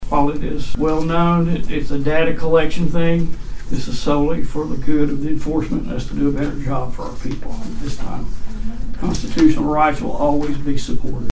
Concerns were raised by some county citizens over the use of such technology, citing privacy concerns and unnecessary surveillance of innocent people. At a Sept. 30 meeting,
Sheriff Scott Owen acknowledged the concern.